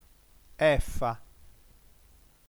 ◊ 1 (sesta lettera dell’alfabeto italiano) f / F f, sa efa:
efa.mp3